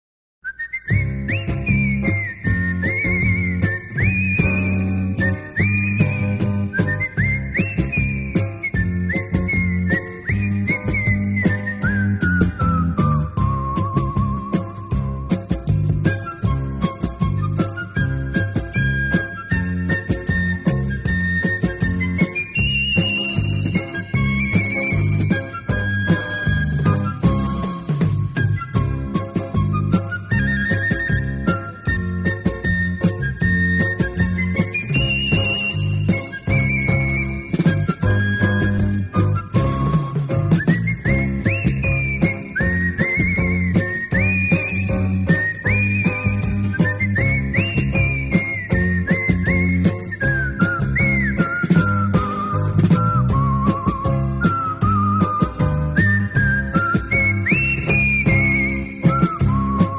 短笛、口哨与乐队：迪克西岛